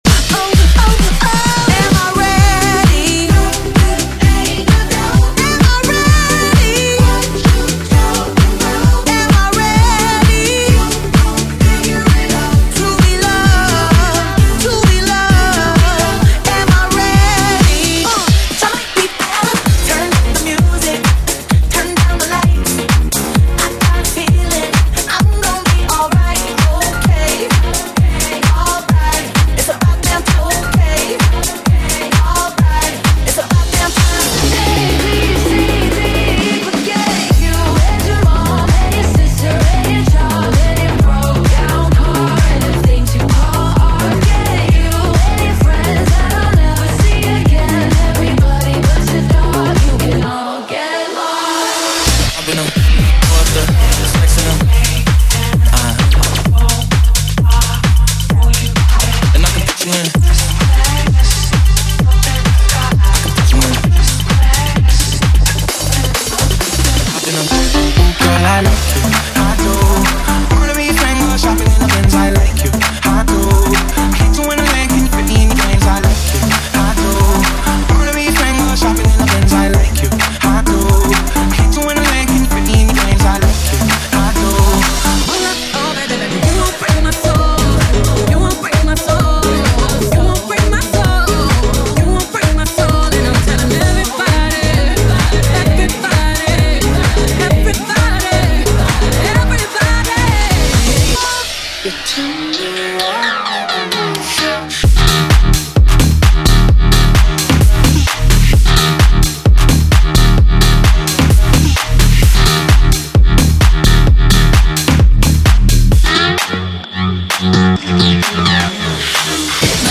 Best 32CT Instructor Mixes.
BPM: 130|140|150 (58:00)